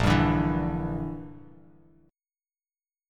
Bb6b5 chord